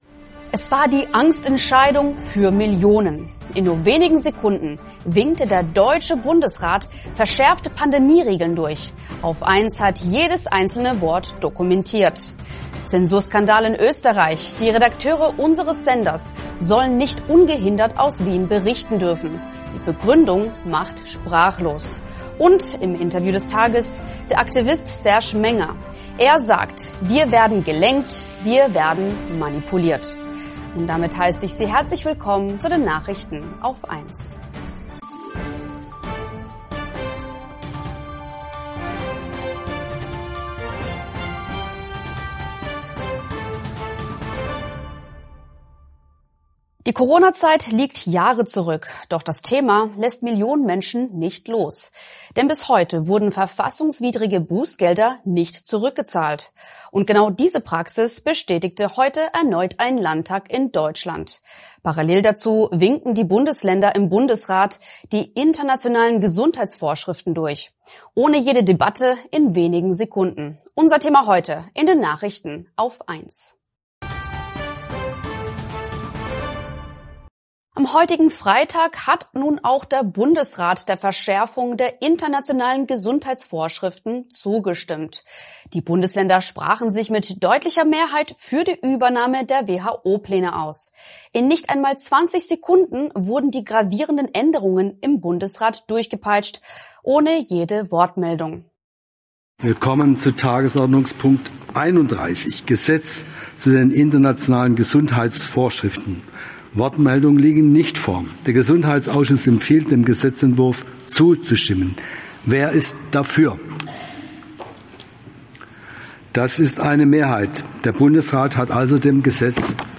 + Und im Interview des Tages.